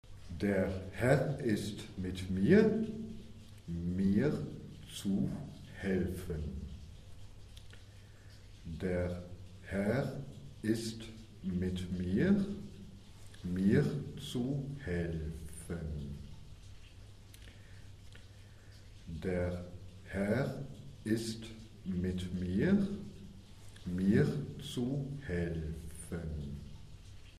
Prononciation
Der Herr ist mit mir, mir zu helfen - lent.mp3